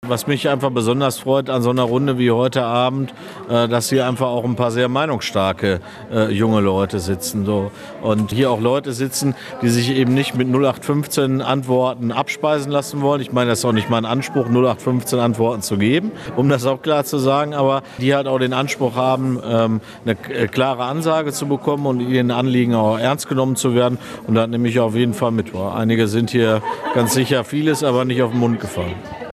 Die Jugendlichen waren während der immer wieder hitzigen Gespräche nicht immer einer Meinung mit Radtke.